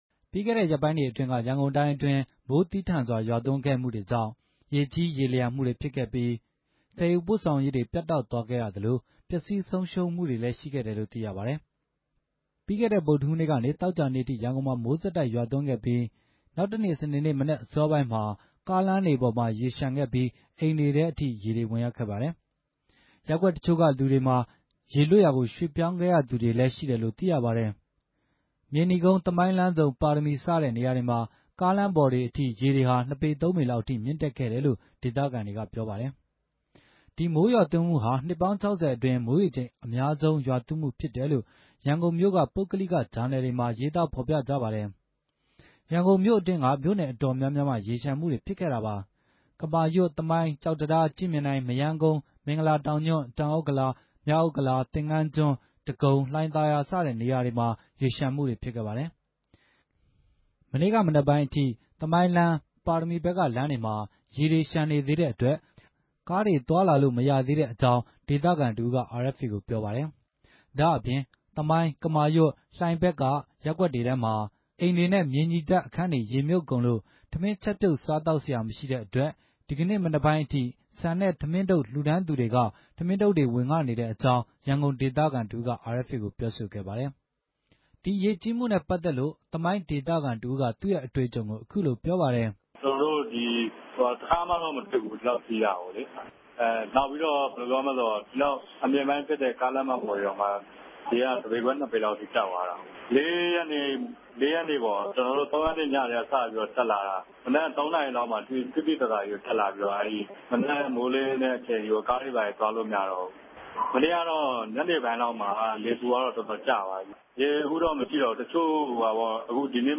မရမ်းကုန်း္ဘမိြႚနယ် ကမႝာအေးမြာ မိုးရေခဵိန် ၁၃လက်မခြဲနဲႛ ၃၉ိံြစ်အတြင်း စံခဵိန်တင်အောင်မိုး႟ြာခဲ့္ဘပီး မဂဿလာဒု္ဘံမိြႚႛနယ်မြာလည်း ိံြစ်၆၀အတြင်း မိုးရေခဵိန် စံခဵိန်သစ်တင်္ဘပီး ႟ြာခဲ့ပၝတယ်။ စမ်းခေဵာင်း၊ ုကည့်ူမင်တိုင်၊ အလုံ၊ ေူမာက်ဒဂုံ၊ တောင်ဒဂုံ၊ လိြင်သာယာ၊ မဂဿလာတောင်ႌြန်ႛ၊ တောင်ဥက္ကလာ ေူမာက်ဥက္ကလာ မရမ်းကုန်း၊ သန်လဵင်၊ တိုက်ဋ္ဌကီး စတဲ့္ဘမိြႚနယ်တေနြဲႛ ရန်ကုန်္ဘမိြႚလယ် နေရာတခဵိြႚမြာ ရေ အတော်ဋ္ဌကီးခဲ့ပၝတယ်။ ဒဂု္ဘံမိြႚသစ်ေူမာက်ပိုင်းက အေူခအနေကိုတော့ ဒေသခံတဦးက ခုလို ေူပာူပပၝတယ်။